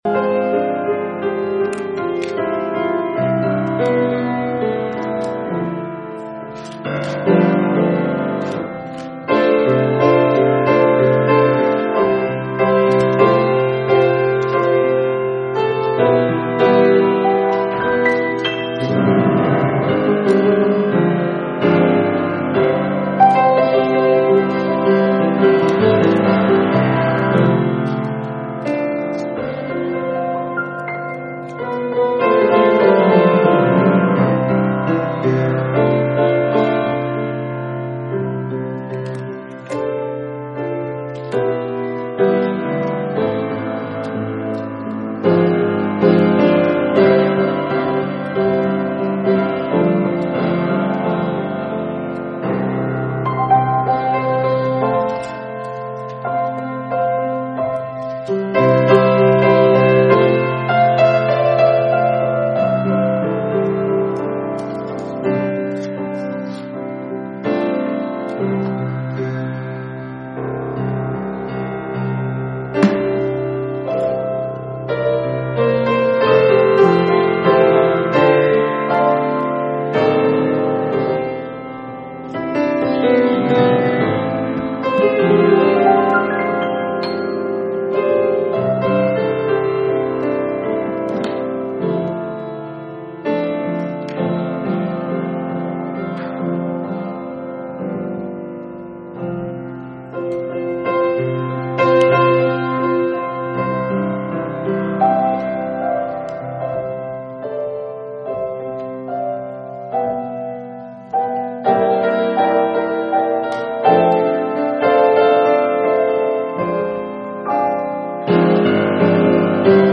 Strength From Weakness – Part 2 – Last Trumpet Ministries – Truth Tabernacle – Sermon Library
Service Type: Sunday Morning